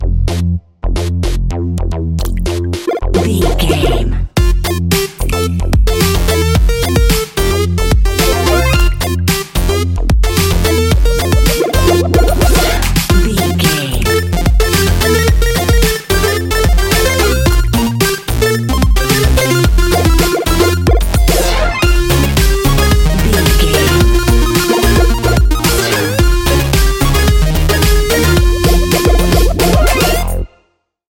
positive and funny electronic music soundtrack
with 8 bit chiptune sounds and a punchy breakbeat
Ionian/Major
synth
bouncy
energetic
drum machine